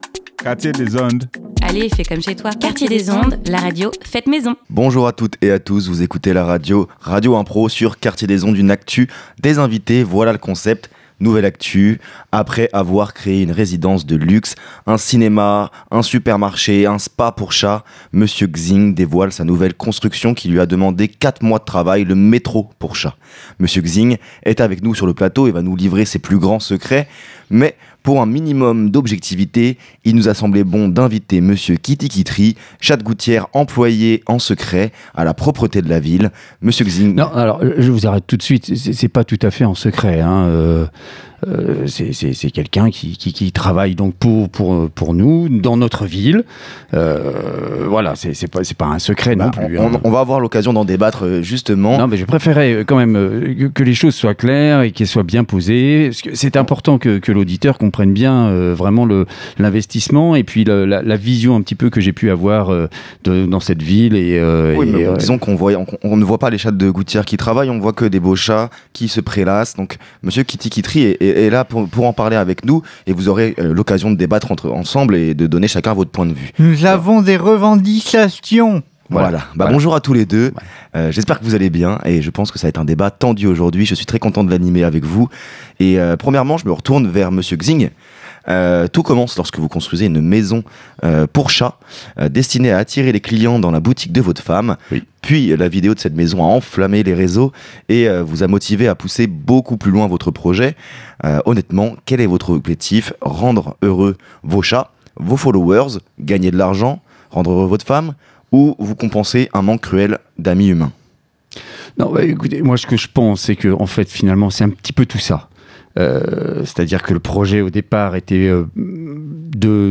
Ce dernier crée de toutes pièces pour Radio Impro un personnage et improvise, bien souvent avec un angle loufoque, des réponses en incarnant sa création. Tout cela crée des interviews tantôt humoristiques, tantôt poétiques, tantôt engagées et parfois un peu de tout ça !